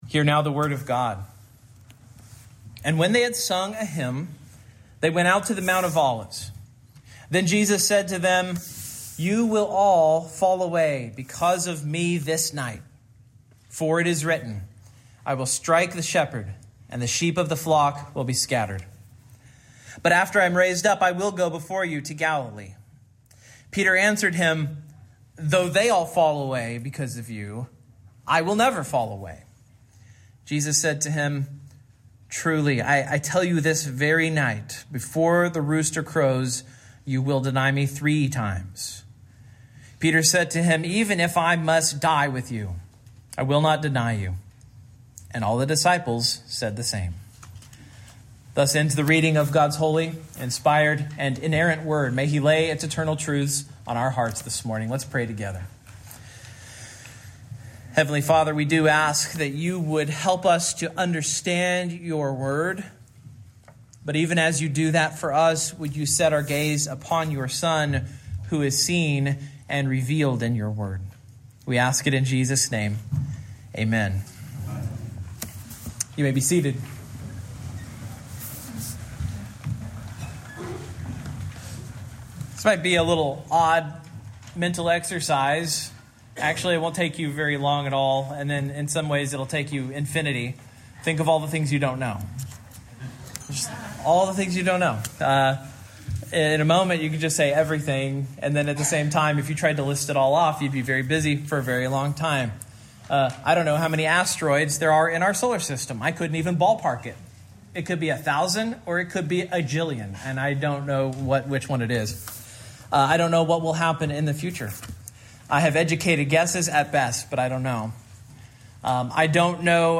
Preacher
Service Type: Morning